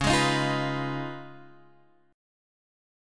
C#9 chord {9 x 6 8 6 7} chord